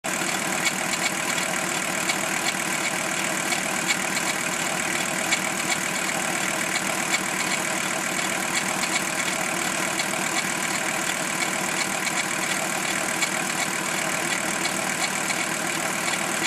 Tere, osaiskohan joku suoraan arvata mikä tuolla rupesi raklattamaan, matkustajan puolelta kuuluu ja muuttuu kaasun mukaan.
raklatus.mp3